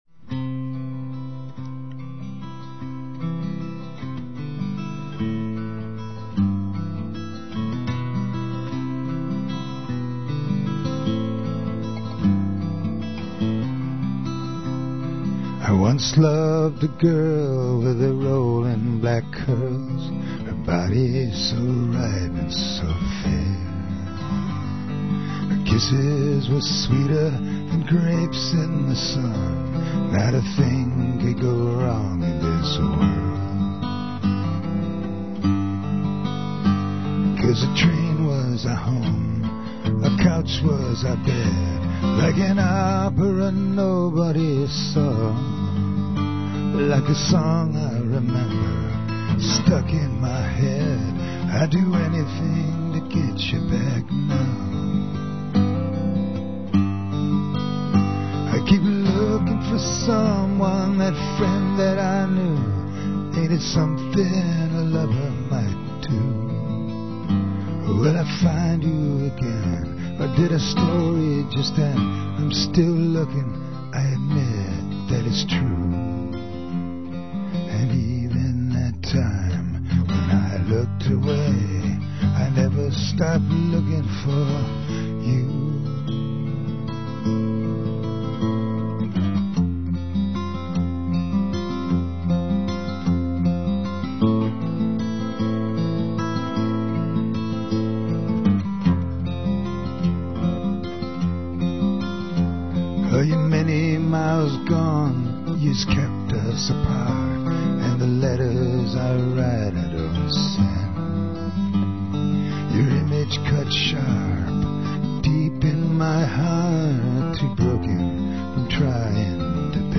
live songs (from radio)
mono